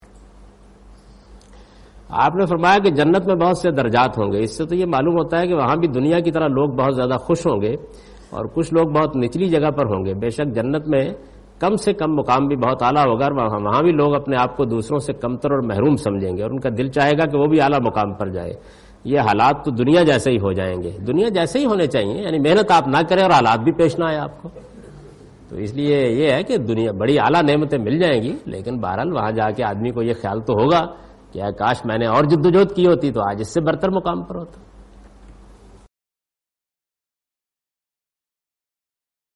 Javed Ahmad Ghamidi responds to the question 'Why lowest to highest ranks in Jannah? This seems like social classes devised by men in this world' ?